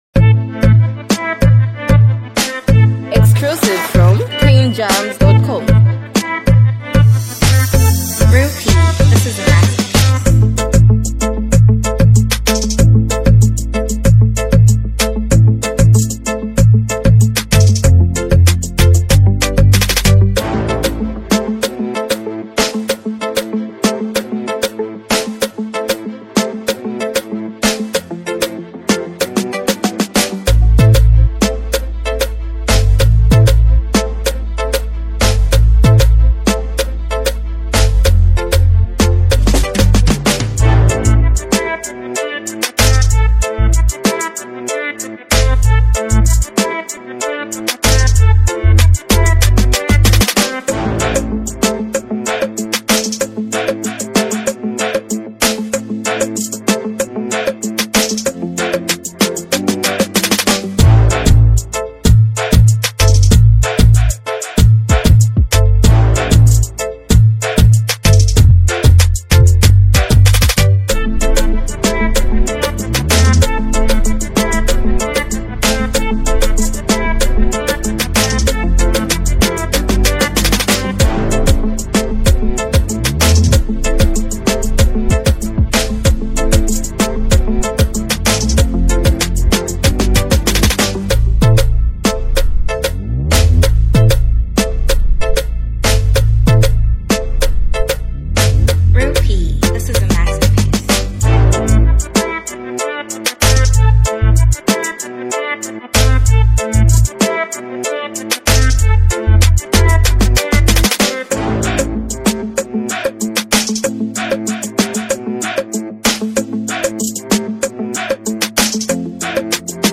commanding delivery and confident lyricism
especially for fans of high-energy Zambian hip-hop.